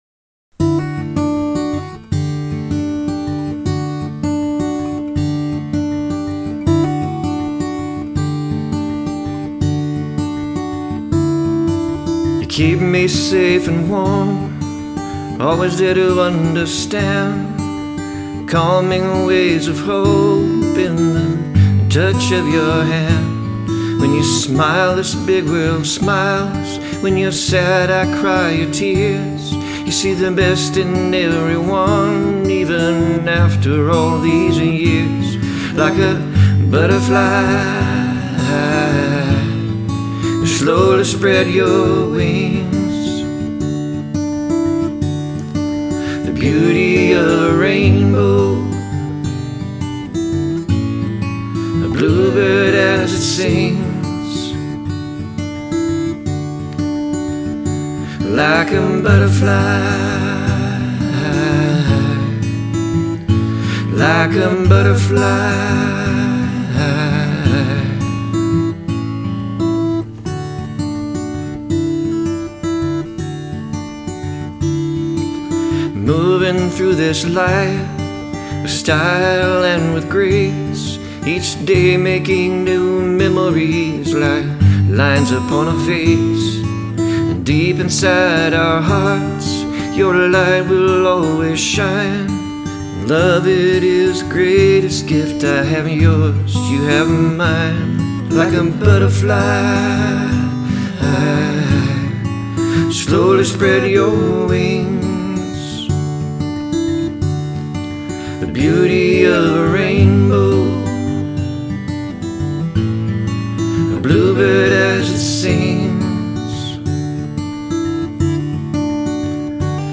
• This song begins in a very sweet and calming way.
• The artist's song has a southern country style of music.